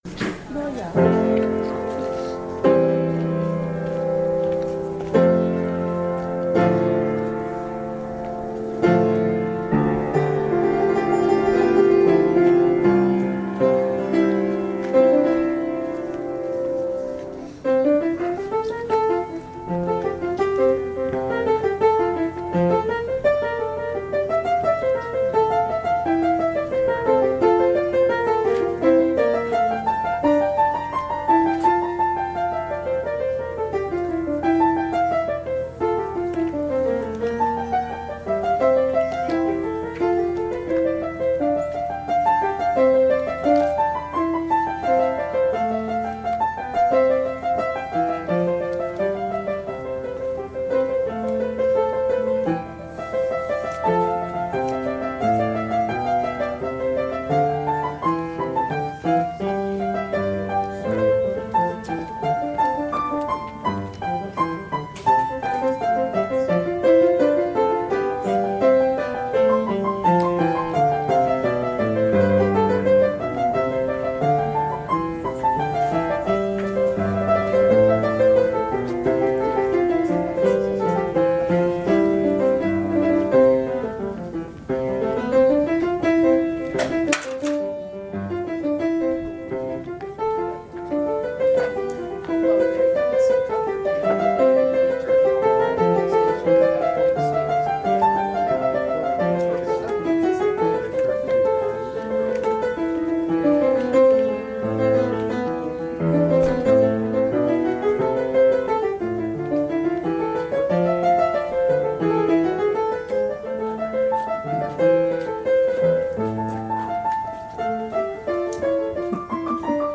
Note: this service was held outside the narthex, without the use of the usual recording system.
Audio recording of the 10am service